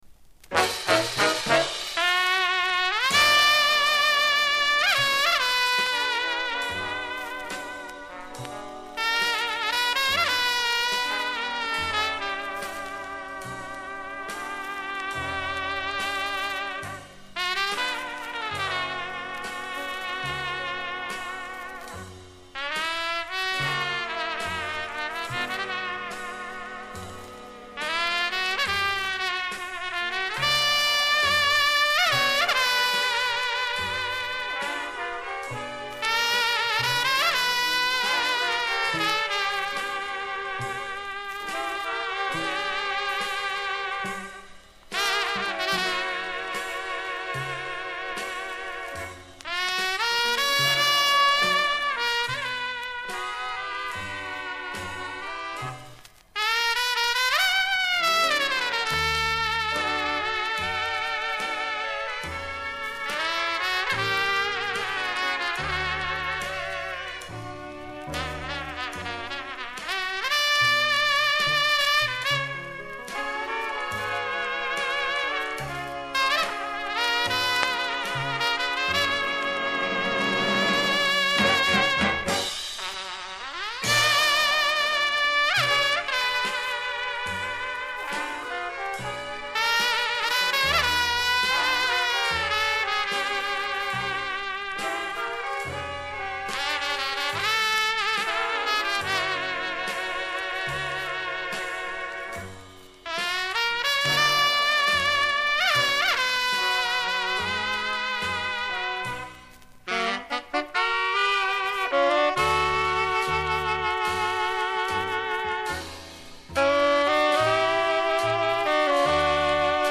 труба
группа ударных инструментов